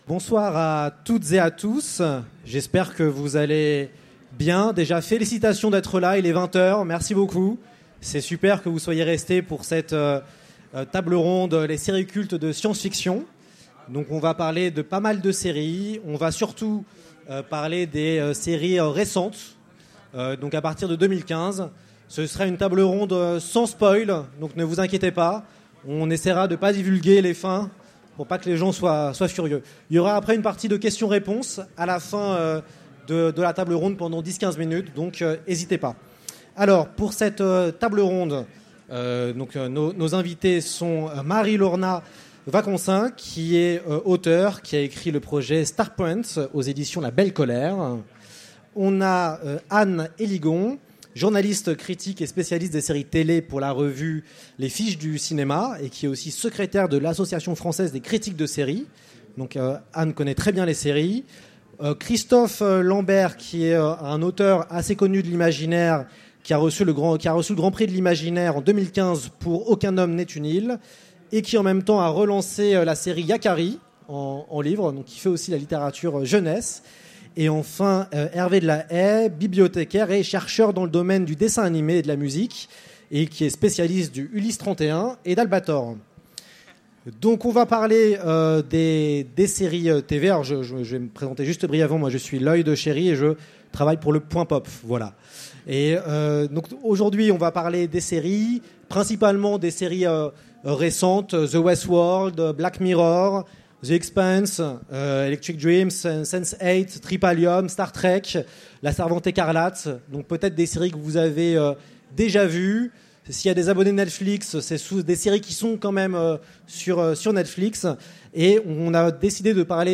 Utopiales 2017 : Conférence Les séries cultes de science-fiction